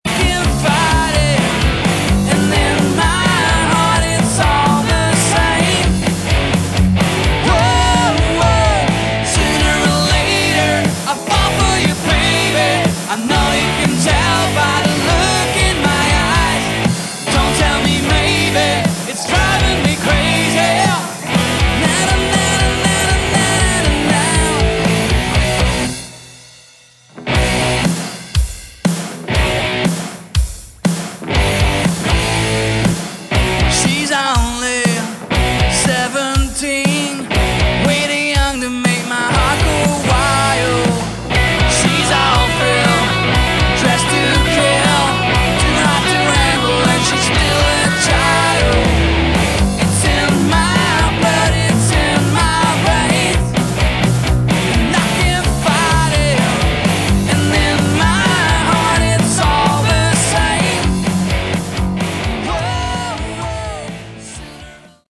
Category: Melodic Rock
Bass
Lead Vocals, Guitar
Drums
Keyboards
Summer 2007 Demo